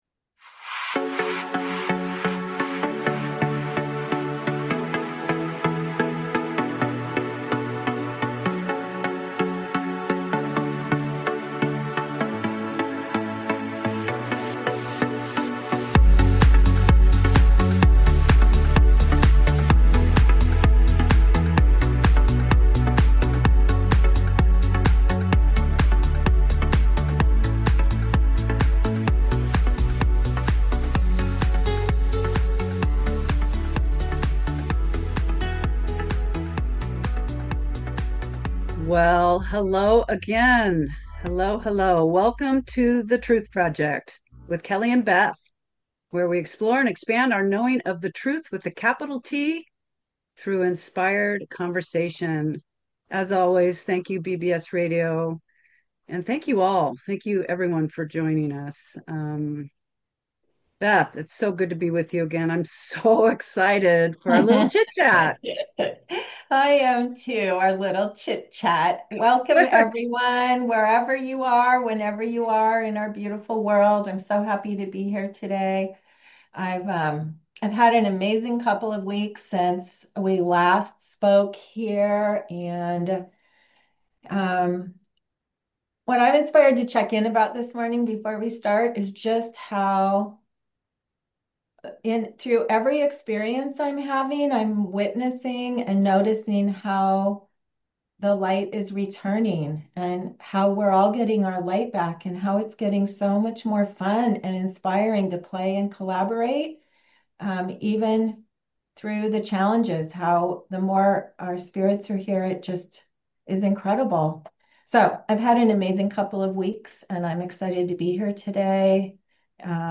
Talk Show Episode, Audio Podcast, The Truth Project and #4: Recognizing Your Inner Voice on , show guests , about The Art of Thinking from the Heart,Navigating Inner Voices and Knowingness,Who is the Inner Voice,Mastering the Mind-to-Heart Drop,the Art of Thinking Backwards,The Hijacked Mind,True spirit communicates via knowingness,Heart-knowing is neutral and silent,Mind-energy is reactive,Witnessing without reaction, categorized as Earth & Space,Health & Lifestyle,Love & Relationships,Philosophy,Physics & Metaphysics,Psychology,Self Help,Society and Culture,Spiritual